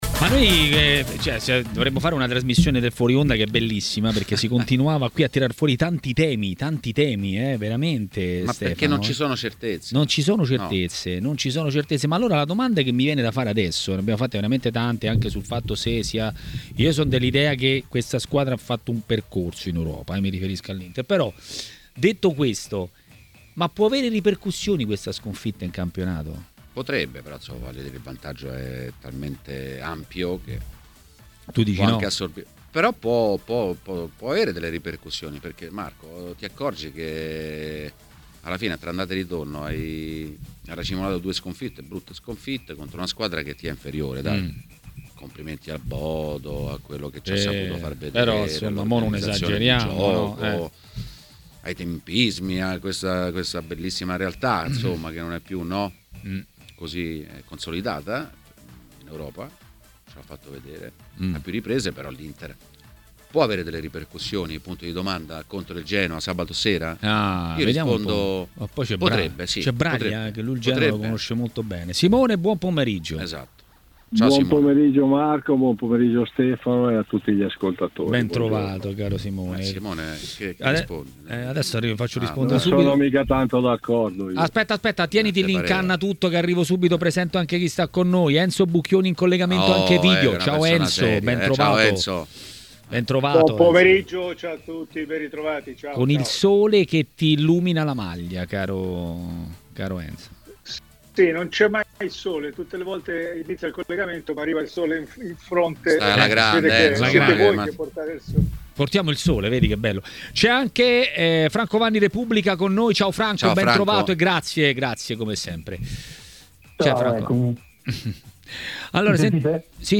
è intervenuto in diretta a TMW Radio, durante Maracanà.